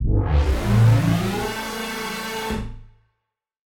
Index of /musicradar/future-rave-samples/Siren-Horn Type Hits/Ramp Up